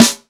Medicated Snare 26.wav